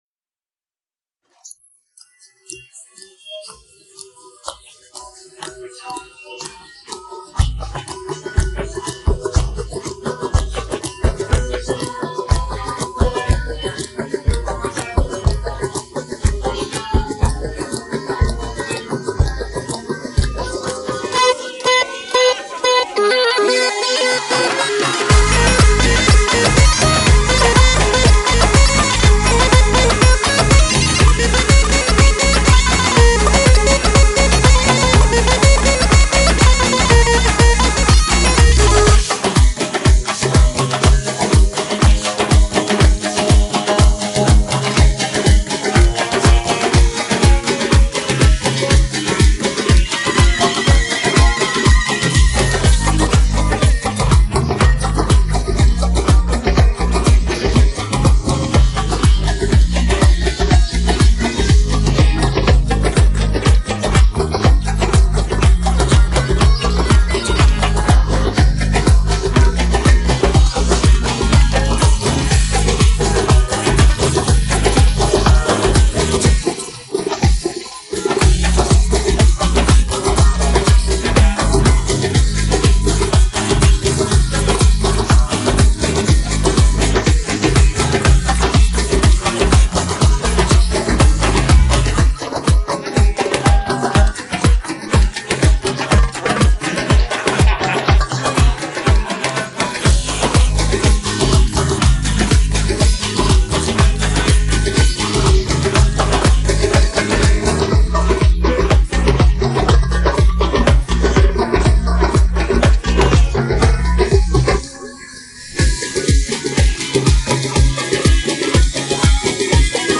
download-cloud دانلود نسخه بی کلام (KARAOKE)